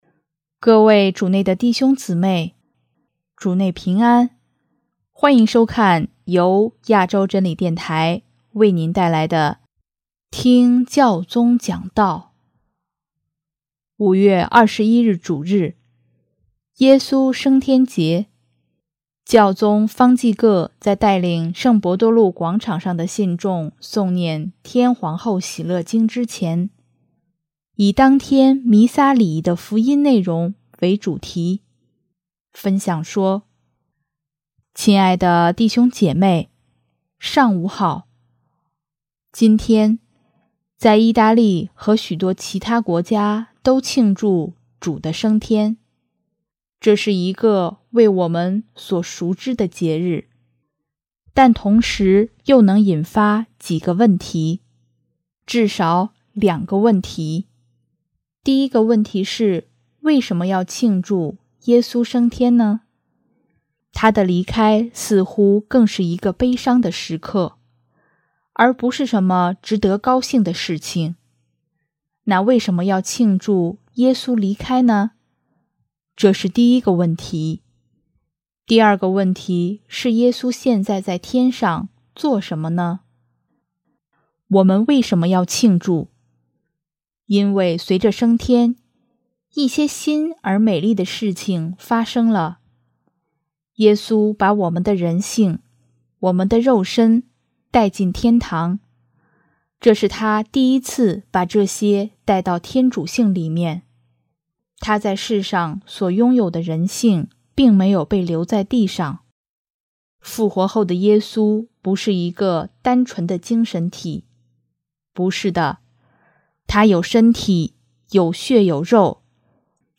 5月21日主日，耶稣升天节，教宗方济各在带领圣伯多禄广场上的信众诵念《天皇后喜乐经》之前，以当天弥撒礼仪的福音内容为主题，分享说：